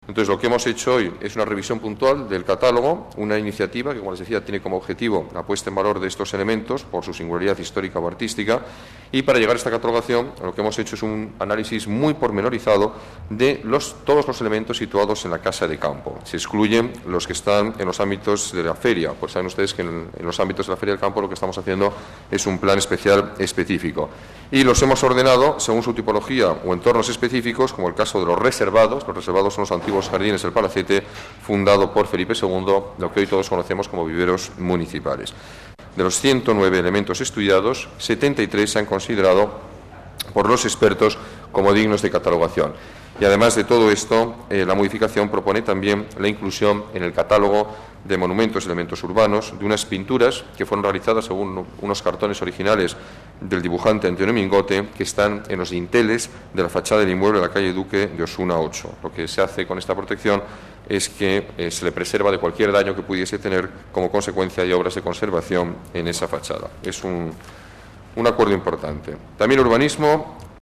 Nueva ventana:Declaraciones Ruiz-Gallardón: Revisión catálogo monumentos Casa Campo